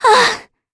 Talisha-Vox_Damage_kr_02.wav